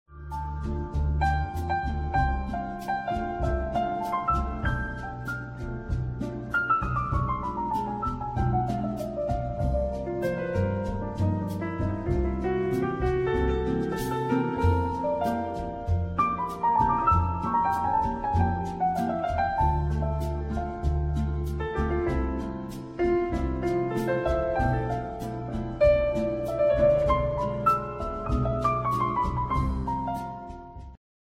ジャンル Jazz
Progressive
癒し系
東洋とも西洋とも言えない新しい宇宙サウンドを聴かせてくれる